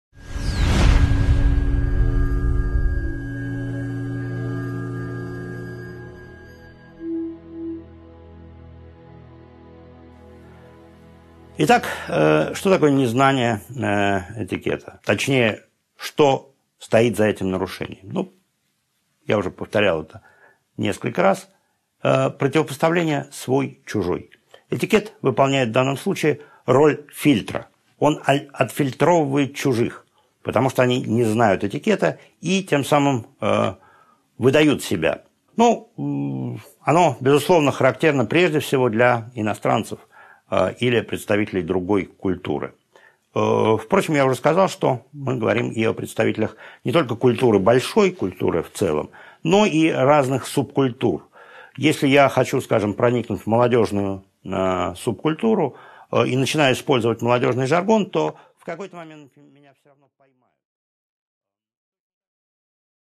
Аудиокнига 9.3 Незнание этикета | Библиотека аудиокниг